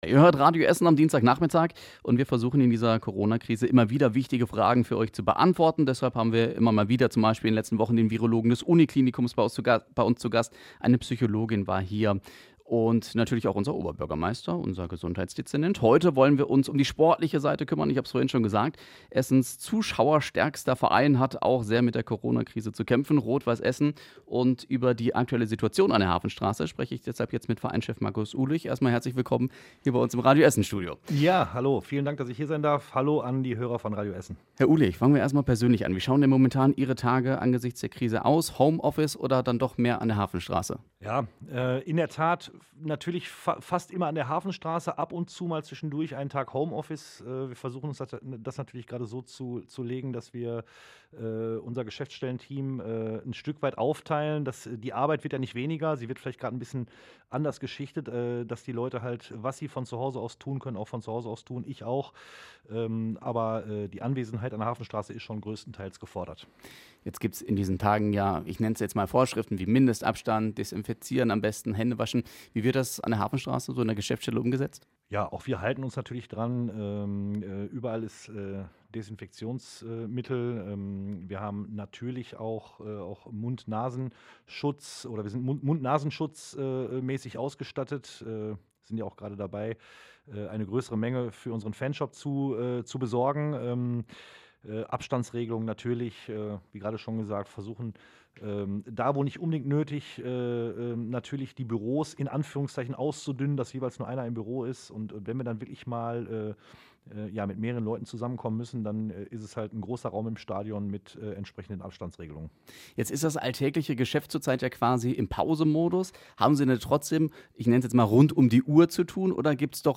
Radio Essen
Eine Stunde lang war er bei uns im Radio Essen-Interview.